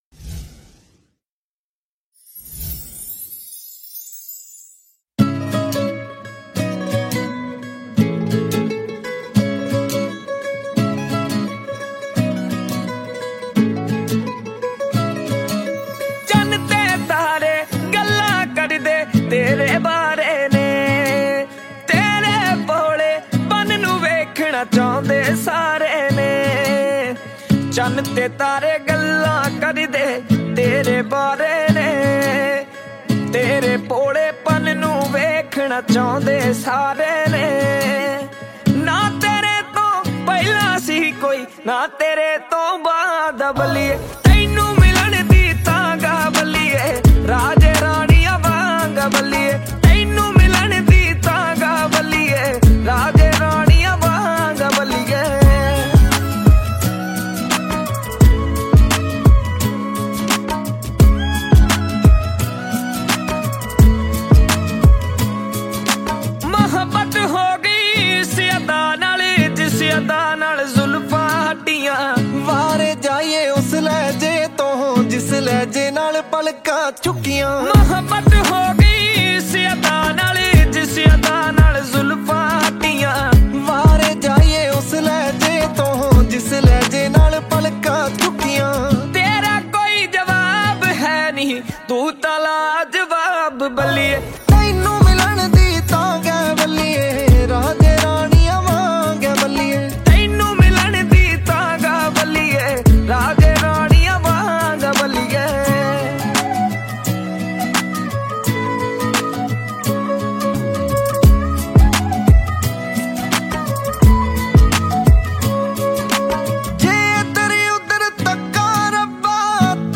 Punjabi Mp3 Songs